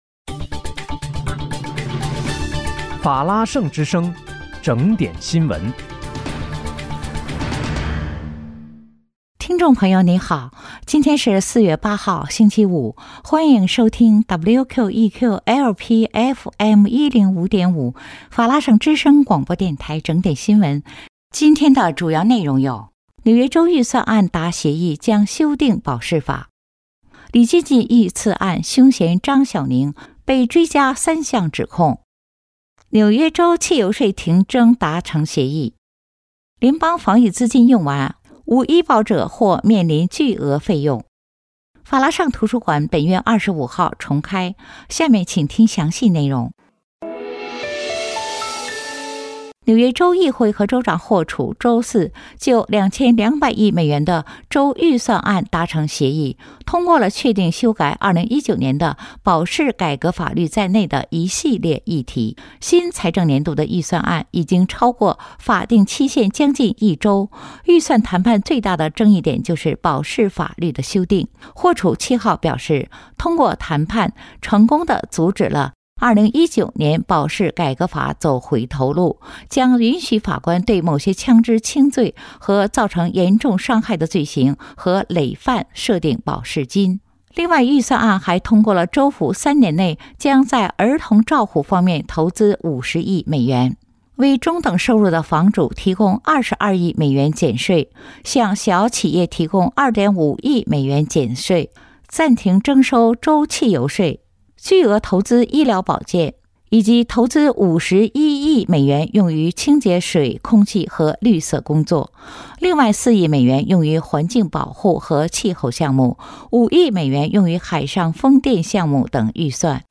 4月8日（星期五）纽约整点新闻